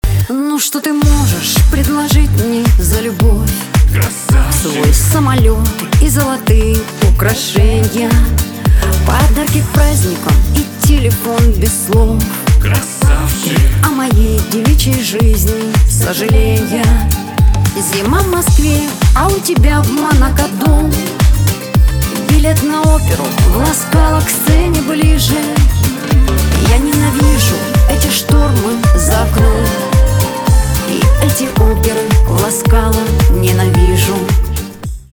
шансон
гитара , грустные
барабаны